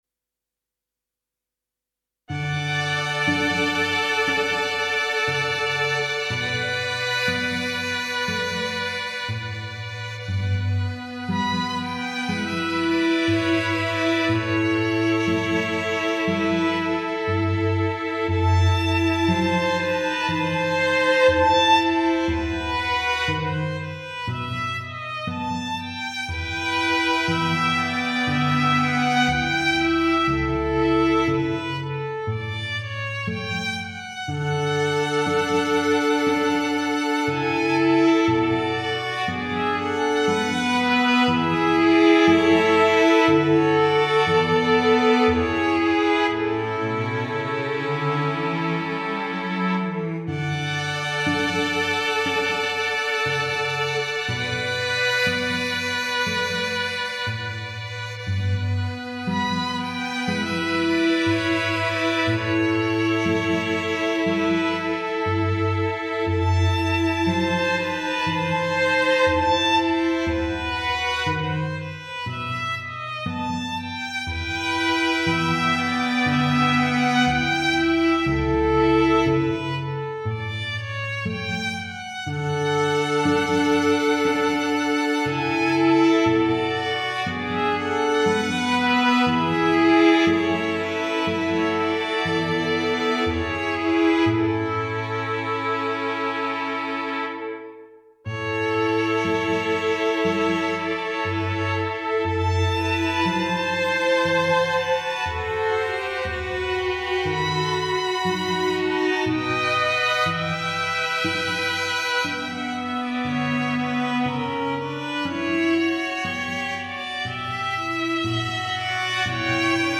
Instrumentation:String Quartet
arranged for string quartet.